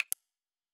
Sound / Effects / UI